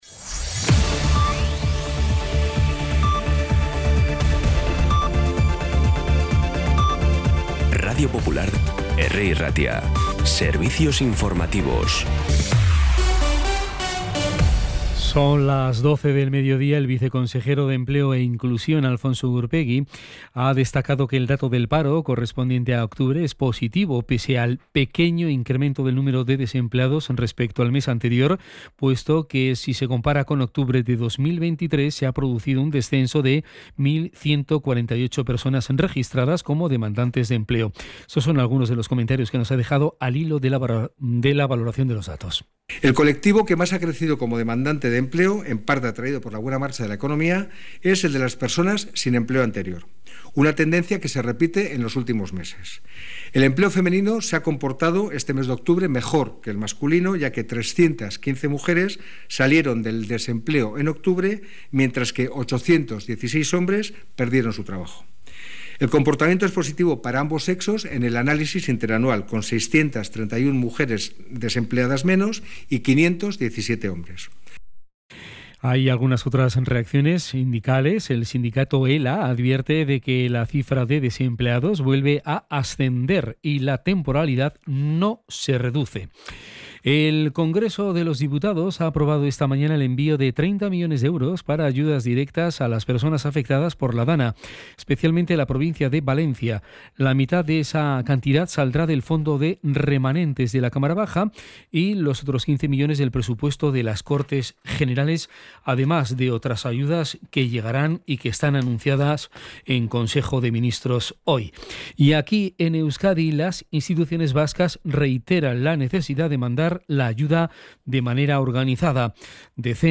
Las noticias de Bilbao y Bizkaia del 5 de noviembre a las 12